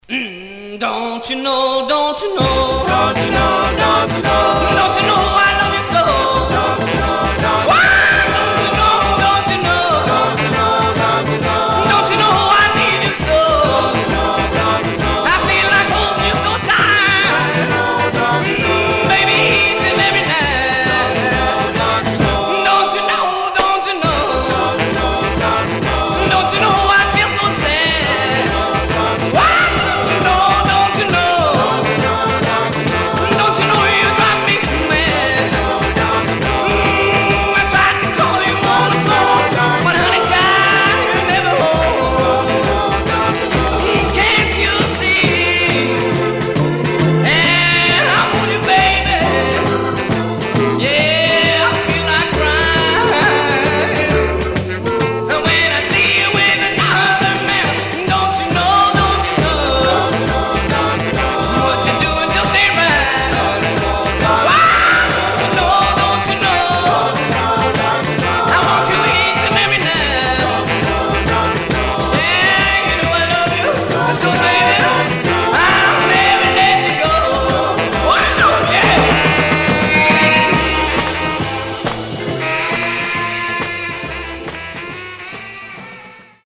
Stereo/mono Mono
Rockabilly